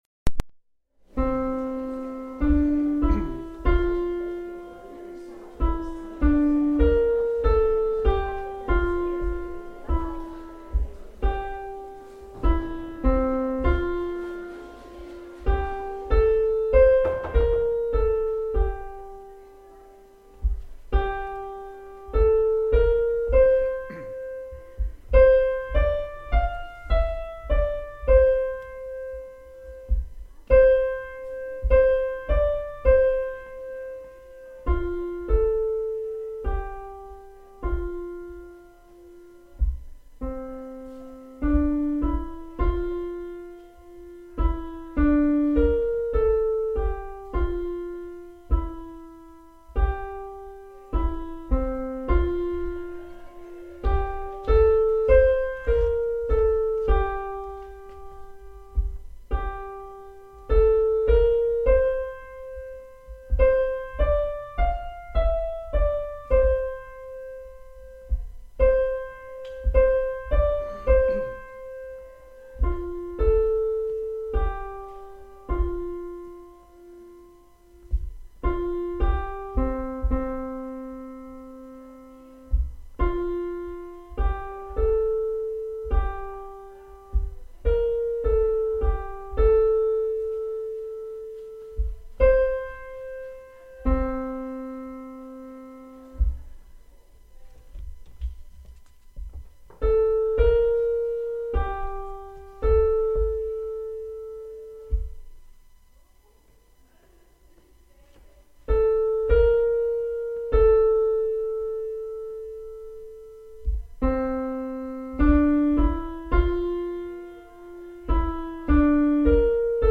Morte Christe: soprano